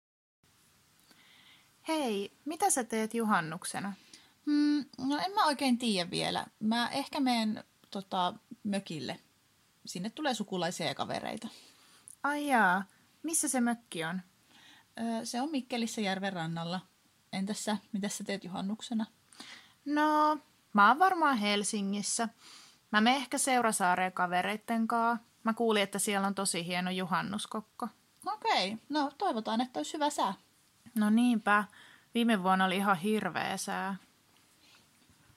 Emma ja Maria juttelevat juhannuksesta.